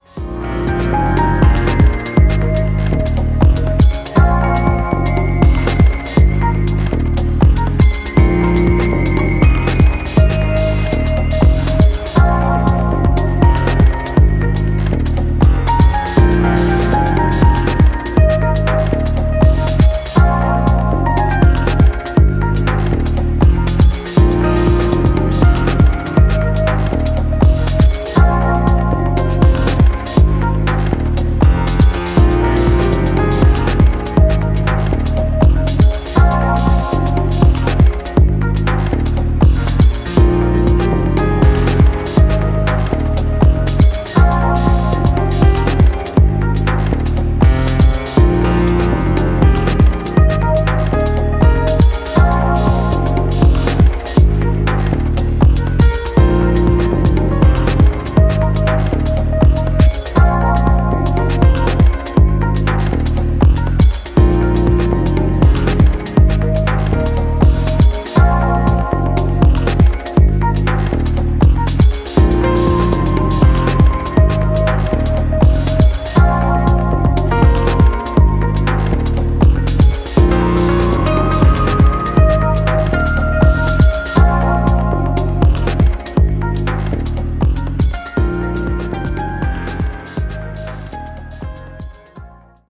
ジャジーでムーディーなテイストや清涼感を纏った良質なディープ・ハウスが揃った大推薦の一枚！！
ジャンル(スタイル) DEEP HOUSE / LOUNGE HOUSE